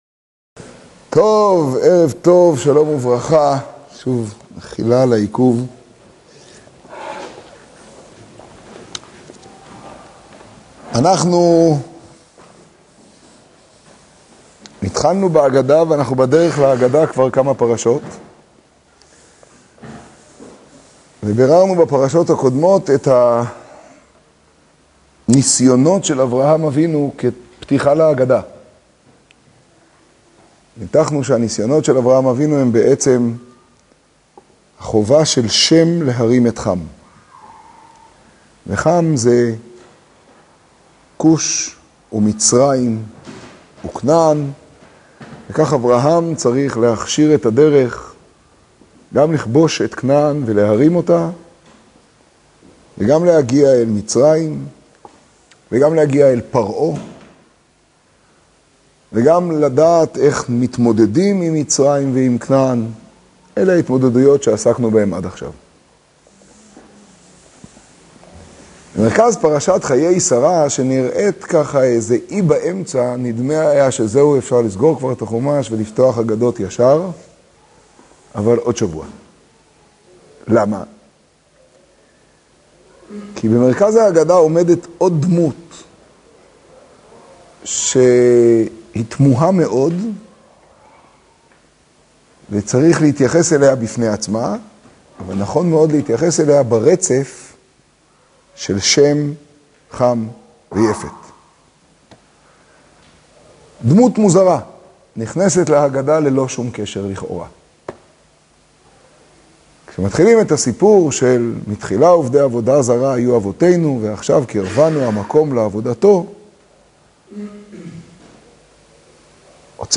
השיעור ברעננה, פרשת חיי שרה תשעג.